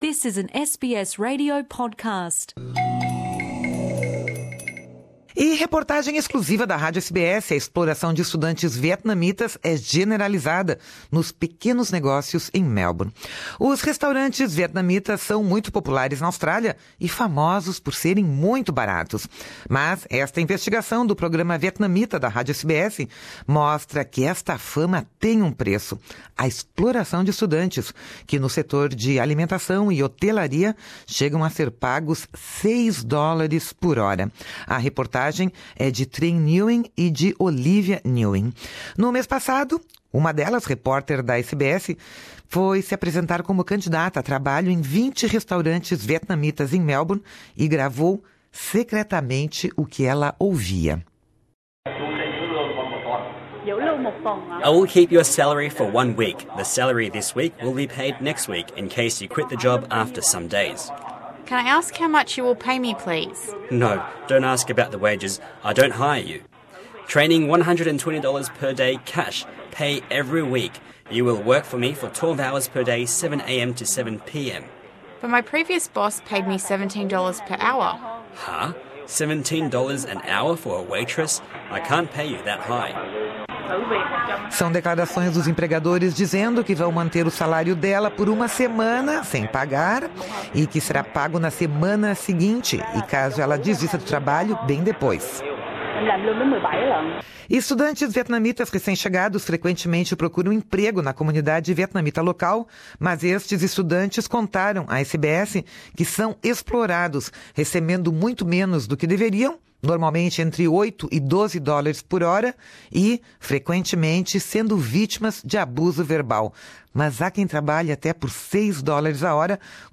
Reportagem exclusiva da Rádio SBS: exploração generalizada de estudantes vietnamitas em pequenas empresas de Melbourne.